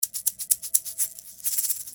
125 bpm shaker (5 variations)
5 Egg shaker loops in 125 bpm.
5 variations of egg shaker, one of the loops will be great for baladi beat.